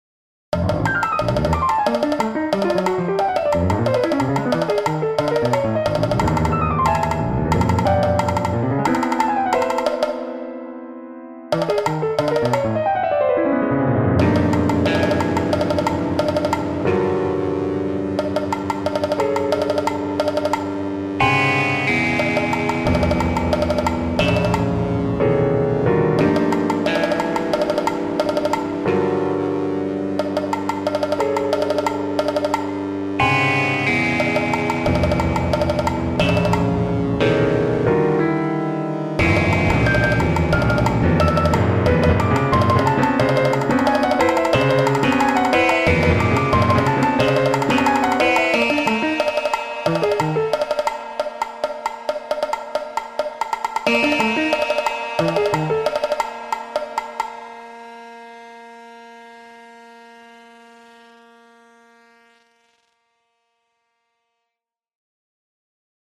Scored for Piano, Bongos and Electric Guitar